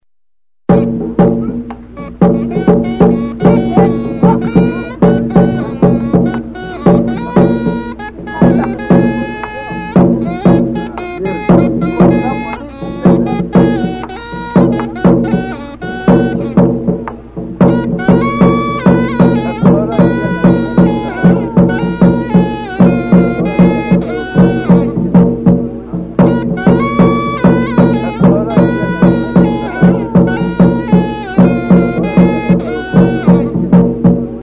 Aerófonos -> Lengüetas -> Doble (oboe)
AMÉRICA -> GUATEMALA